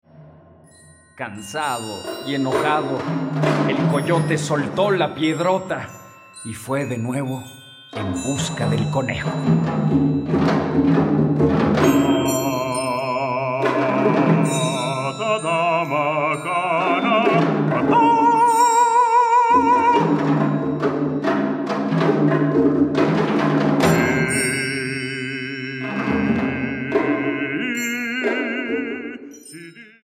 ópera infantil